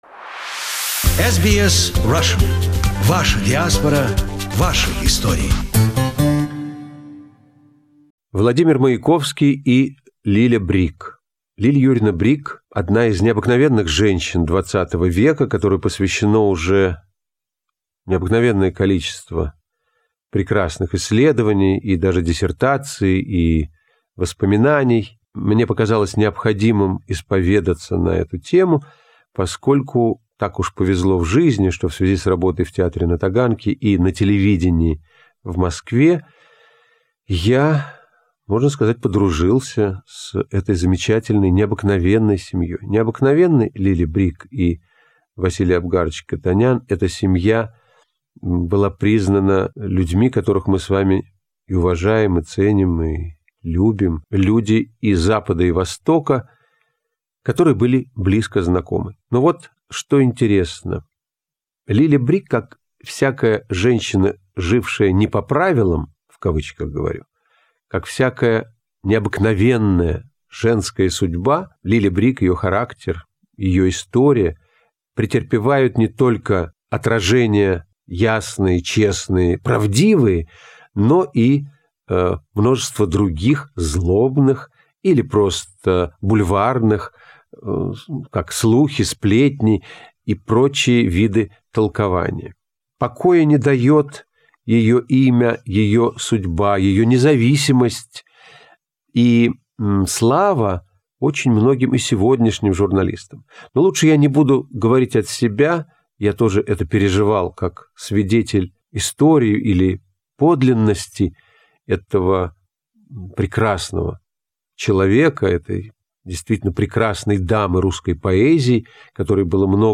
His concerts were a big success in Australia, and we asked him to record some of his material for the radio. Fortunately the recordings survived and we are happy to preserve them on our web archives In this recording he speaks about one of the most prominent romantic relationships in the Soviet history of literature - between poet Vladimir Mayakovsky and his beloved muse Lily Brik, a socialite, connected to many leading figures in the Russian avant-garde